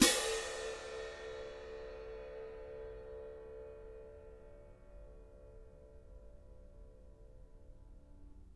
cymbal-crash1_pp_rr2.wav